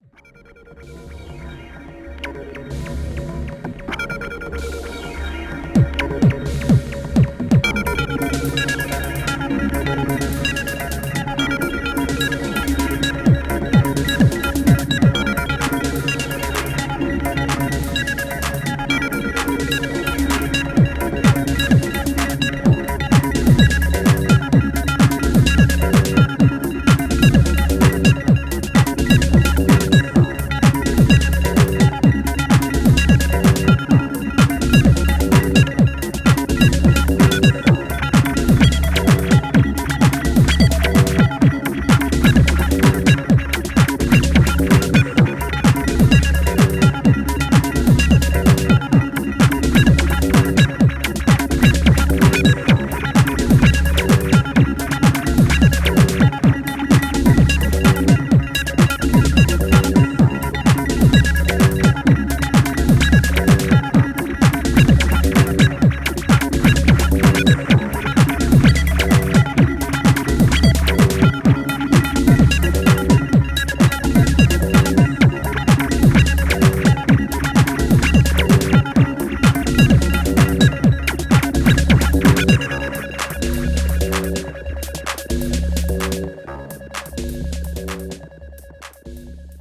アブストラクトなブリープチューン
ポスト・パンキッシュ・レイヴ！